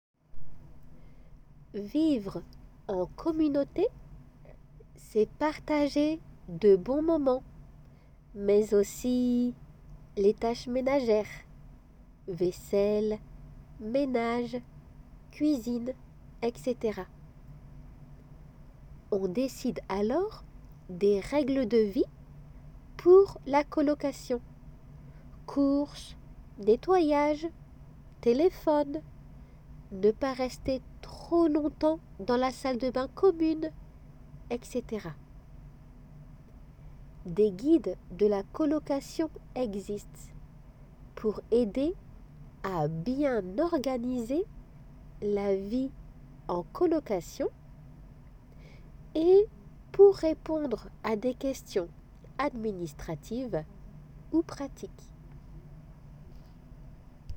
聞き取り練習　練習用のデイクテ
普通の速さで